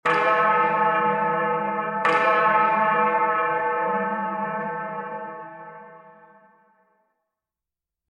Scary Bells Sound Button - Free Download & Play